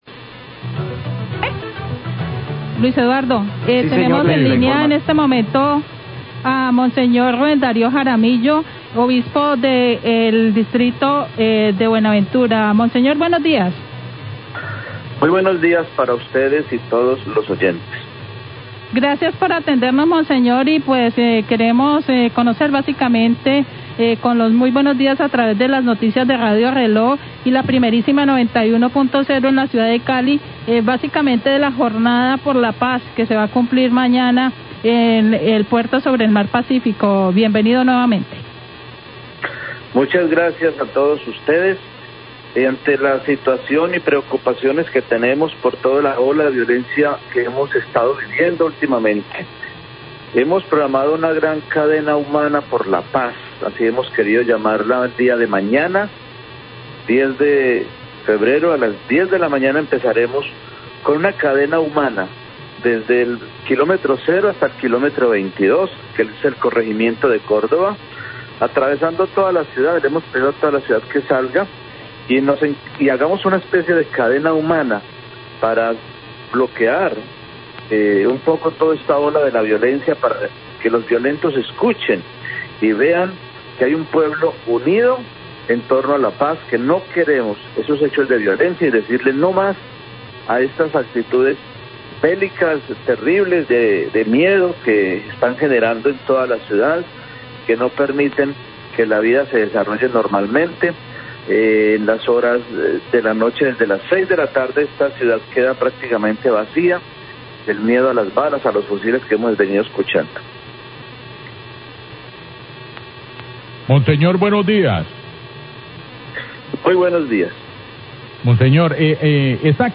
Obispo de Buenaventura habla de la cadena humana contra la violencia
Radio
El Obispo de Buenaventura, Ruben Dario Jaramillo, habla sobre la cadena humana por la paz que los bonaverenses formaron en las calles de la ciudad para protestar contra la violencia.